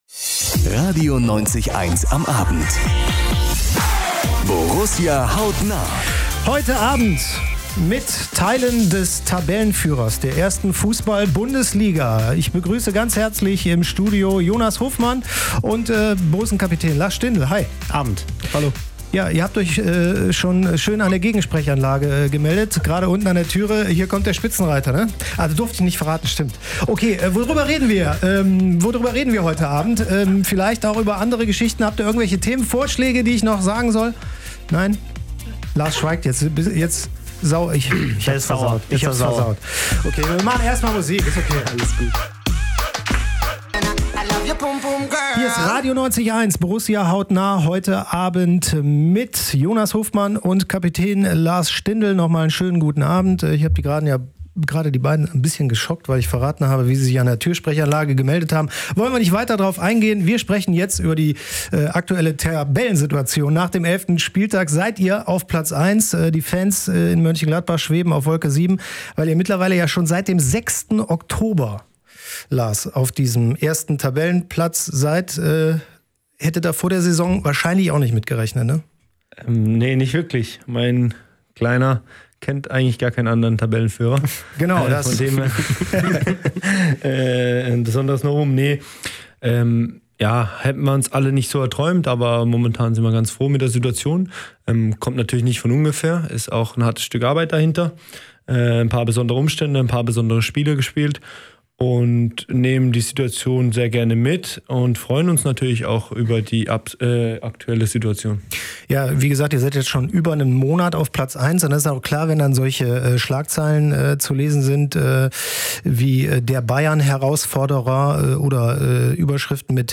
Am Mittwoch (13.11.) waren Lars Stindl und Jonas Hofmann bei RADIO 90,1 im Studio - hier gibt es das Interview im Podcast.